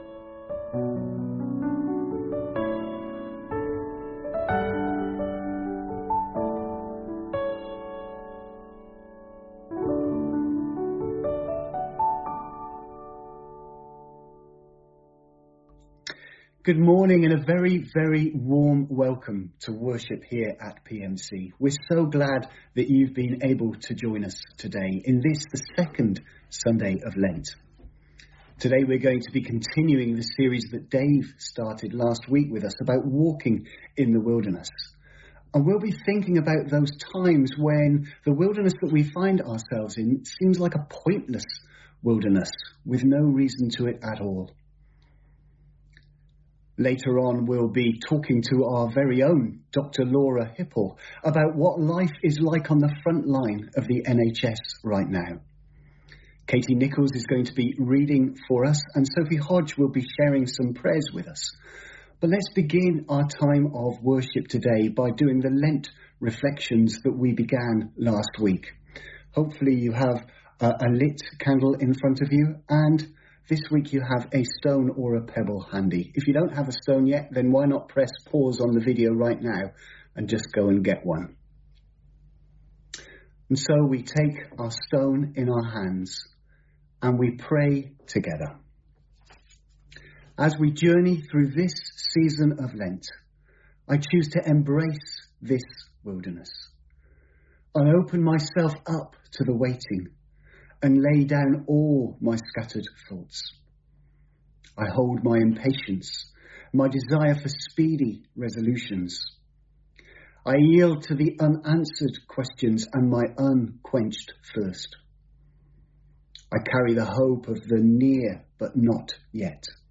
From Service: "10.30am Service"